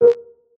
Content / Sounds / UI / ChatMsg.ogg
ChatMsg.ogg